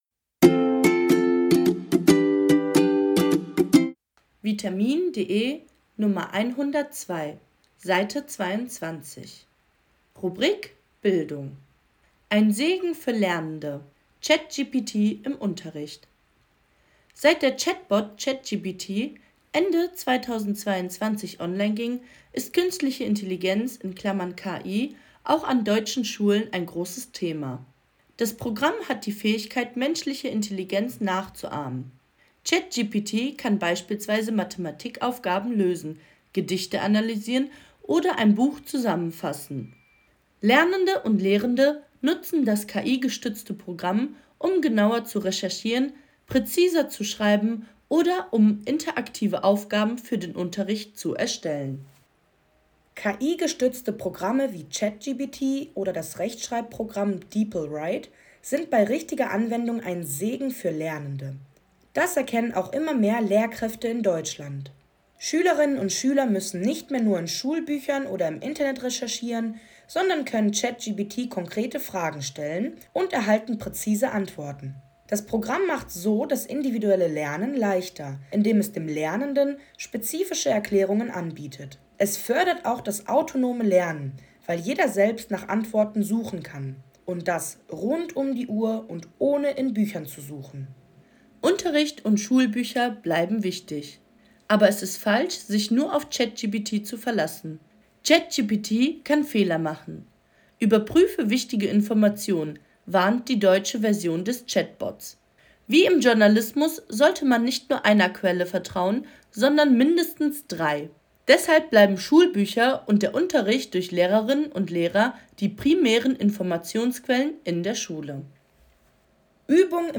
Audiodatei (Hörversion) zum Text
Intro-Melodie der Audios